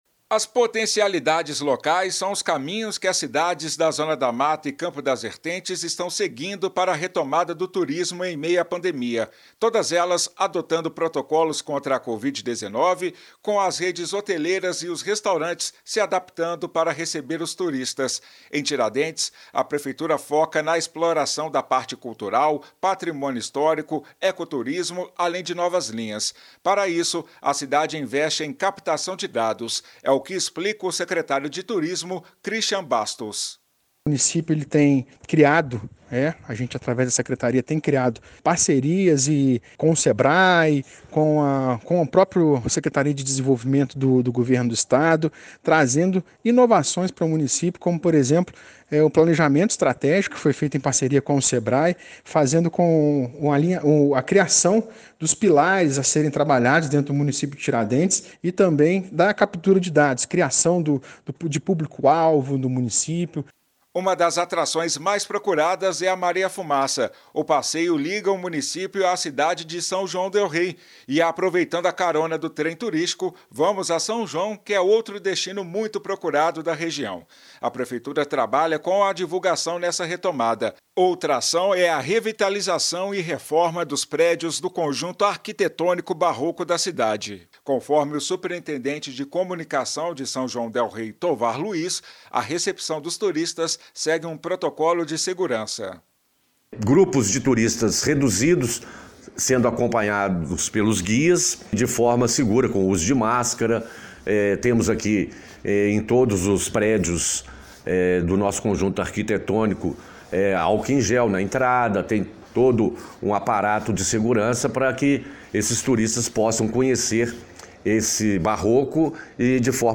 Municípios da Zona da Mata e do Campo das Vertentes estão atuando na retomada do turismo em meio à pandemia da covid-19. Secretários e integrantes das Prefeituras explicaram as estratégias à nossa reportagem.